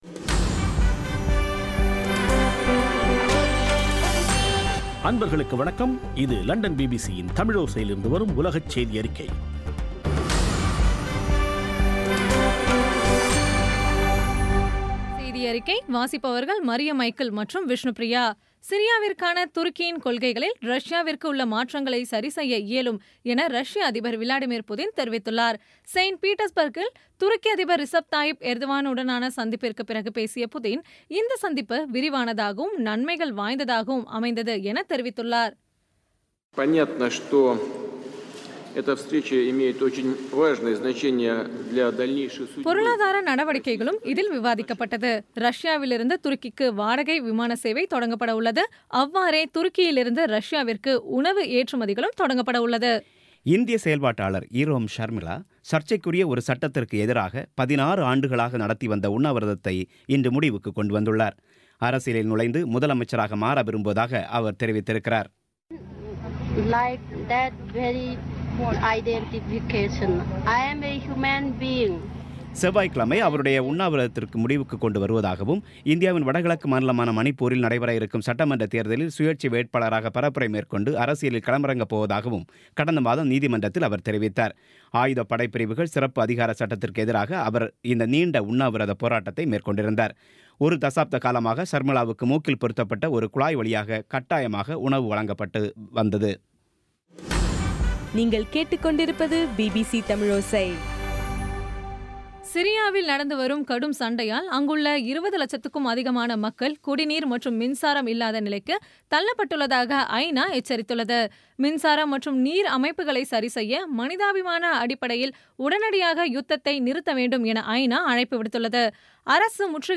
பி பி சி தமிழோசை செய்தியறிக்கை (09/08/2016)